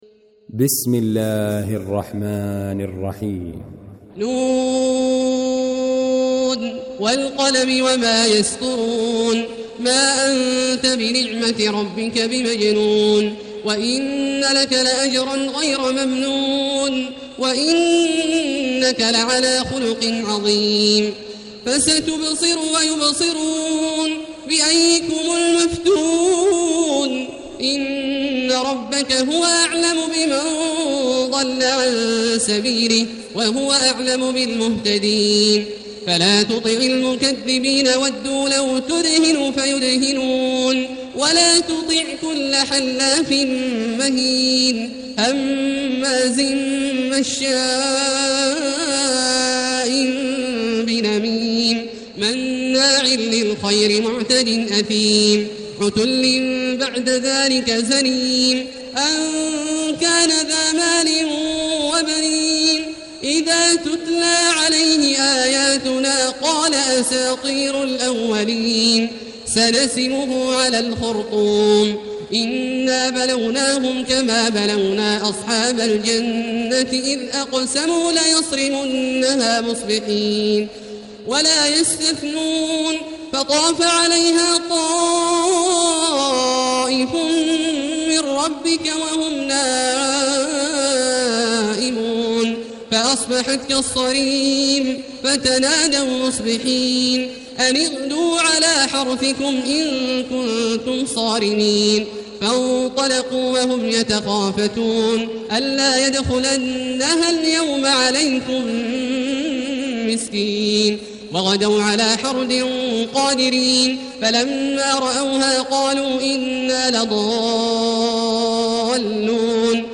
المكان: المسجد الحرام الشيخ: فضيلة الشيخ عبدالله الجهني فضيلة الشيخ عبدالله الجهني القلم